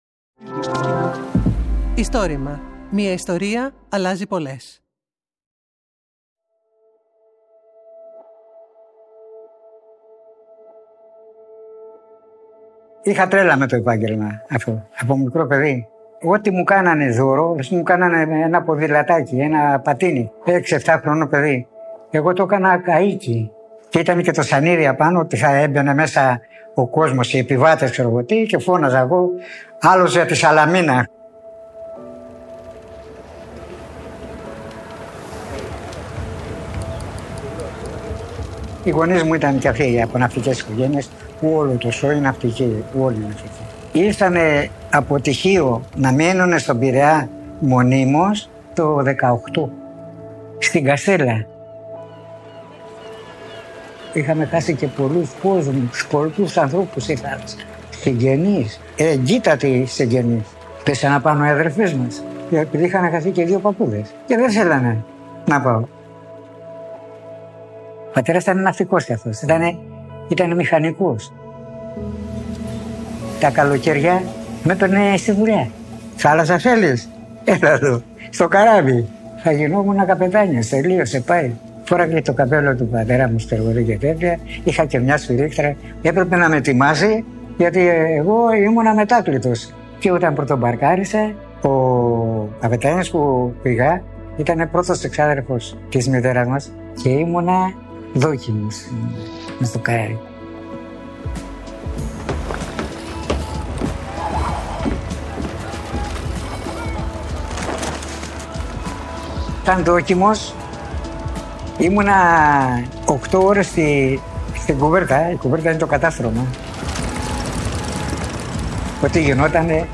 Το Istorima είναι το μεγαλύτερο έργο καταγραφής και διάσωσης προφορικών ιστοριών της Ελλάδας.